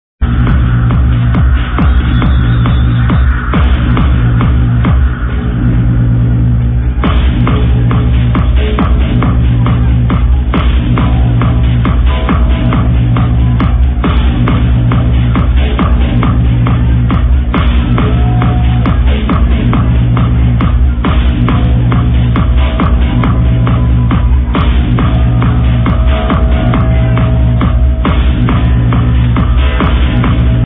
yup ty, its just some dub or something i think